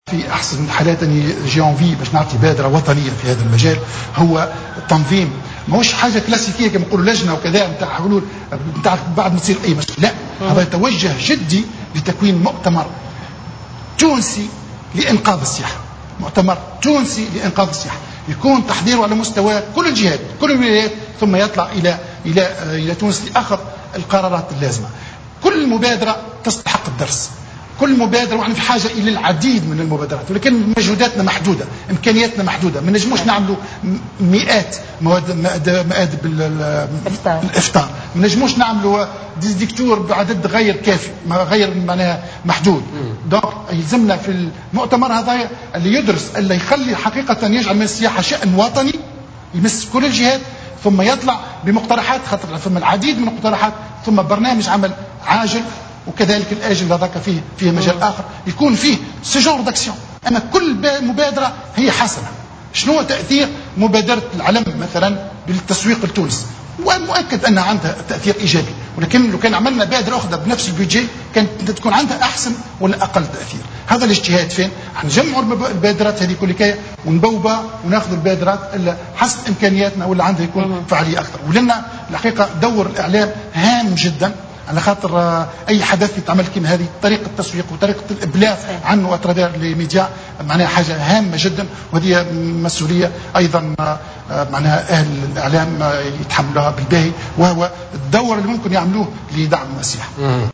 اقترح وزير السياحة الأسبق،جمال قمرة في مداخلة له على "جوهرة أف أم"مبادرة لإنقاذ القطاع السياحي.